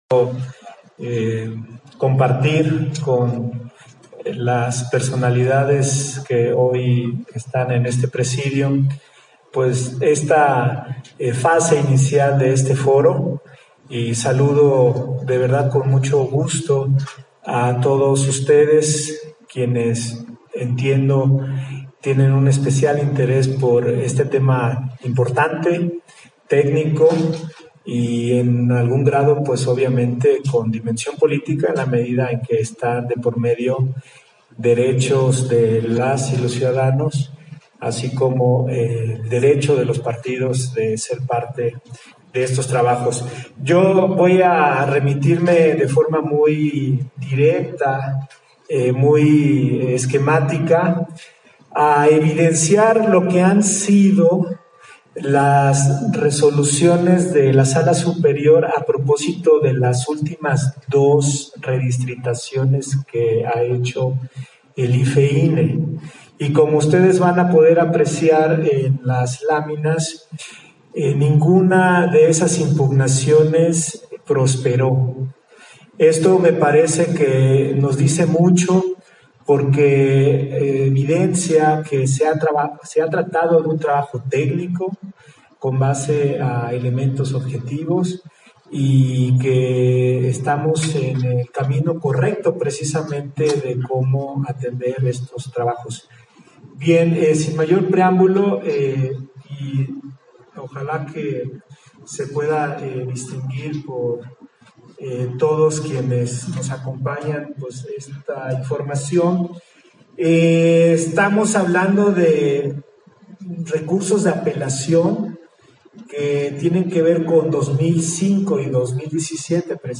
301121_AUDIO_INTERVENCIÓN-CONSEJERO-RUIZ-FORO-ESTATAL-DE-DISTRITACIÓN-CDMX - Central Electoral